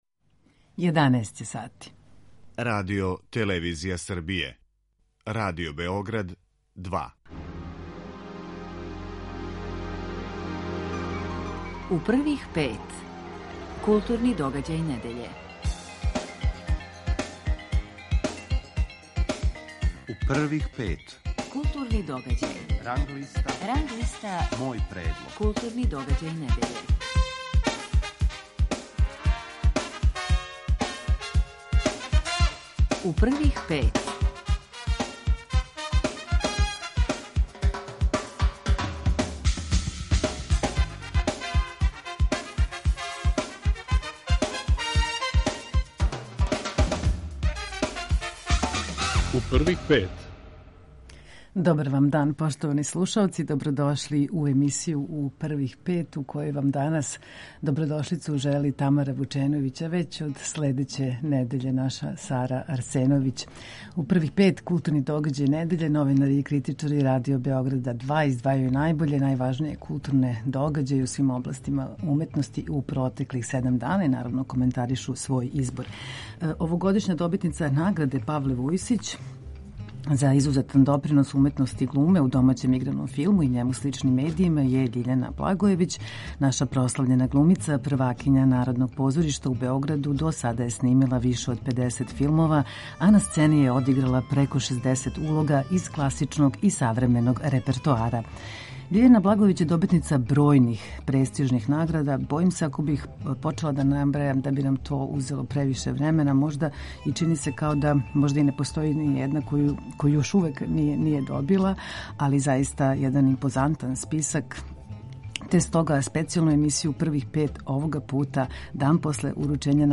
Гошћа емисије је глумица Љиљана Благојевић.
Специјалну емисију У првих пет овога пута, дан после уручења награде, реализујемо из студија Нишког дописништва Радио Београда 2, где ћемо се Љиљаном Благојевић разговарати о изазовима одабира правих улога, четрдесетогодишњици култног филма „Сјећаш ли се Доли Бел", серијама „Кљун" и „Халијард", у којима ћемо је ускоро гледати...